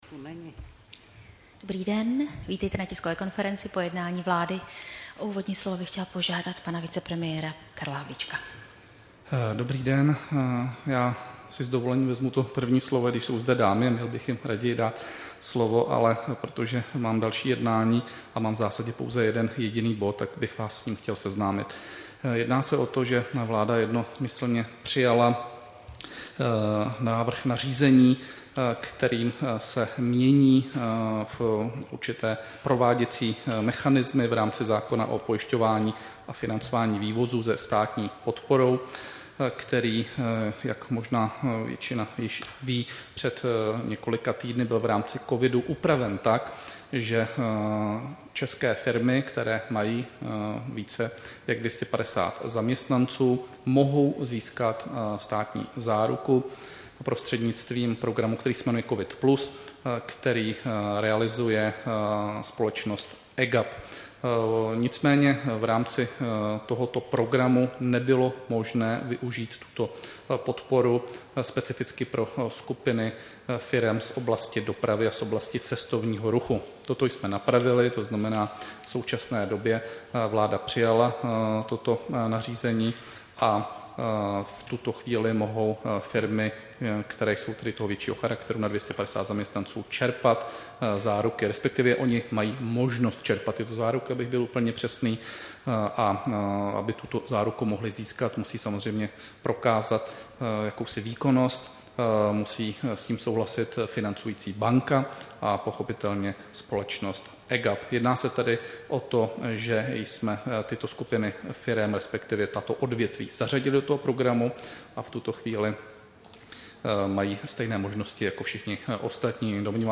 Tisková konference po jednání vlády, 29. června 2020